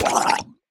sounds / mob / drowned / hurt1.ogg
hurt1.ogg